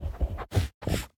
Minecraft Version Minecraft Version 25w18a Latest Release | Latest Snapshot 25w18a / assets / minecraft / sounds / mob / sniffer / searching6.ogg Compare With Compare With Latest Release | Latest Snapshot
searching6.ogg